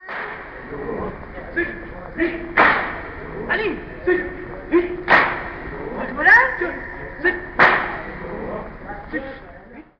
In der einen marschieren die Trauernden mit Schlaginstrumenten (eine Art von Becken und Busch- oder Nachrichtentrommel) musizierend durch die Straßen.
Dieser steht in der Mitte umringt von dicht stehenden Massen und stimmt Klagelieder an. Dabei wird sein Gesang von der Menge rhythmisch mit Schlagen der Hände auf die Brust begleitet. Die Menschenspirale dreht sich zusätzlich um den Trauersänger herum und sobald er verstummt, wiederholen die Trauenden die letzten vorgesungenen Strophen.